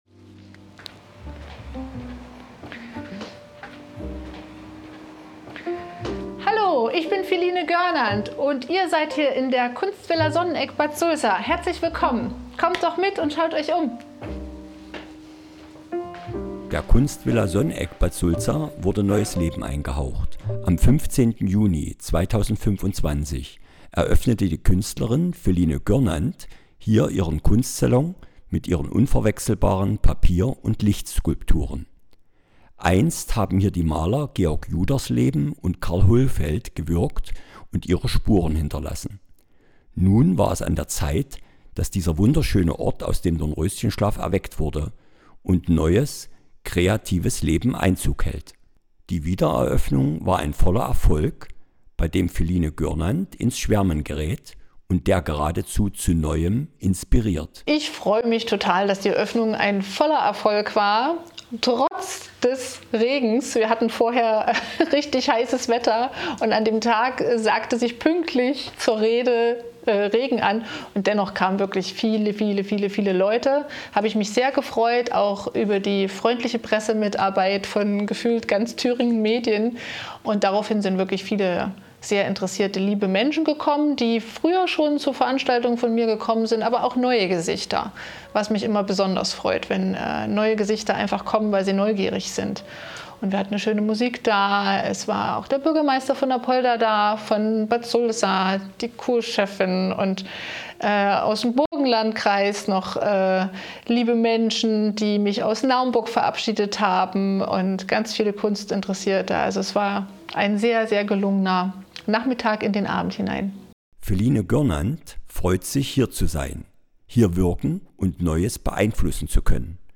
Uwe Barth l�dt ein zur Diskussion auf der Th�ringen Ausstellung.